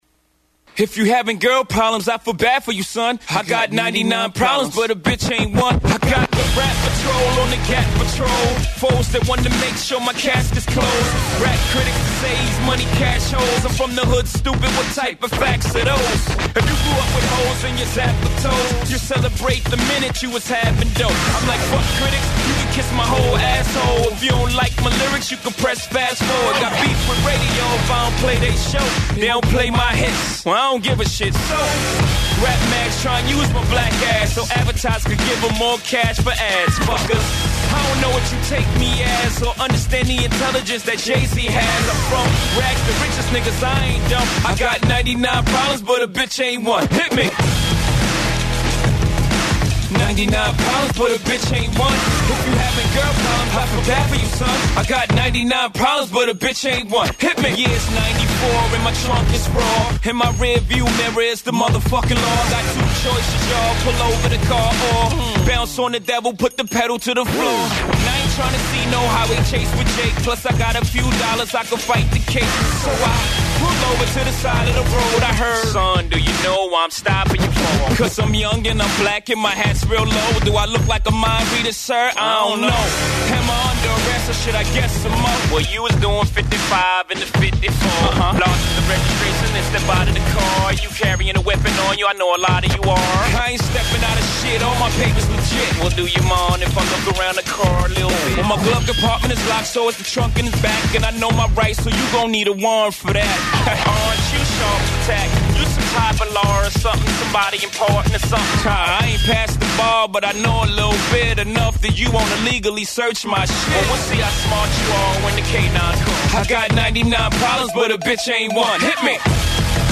Inkstuds: Interview with Brian K. Vaughan and Pia Guerra of Y: The Last Man
The inkstuds were joined by two stellar creators today.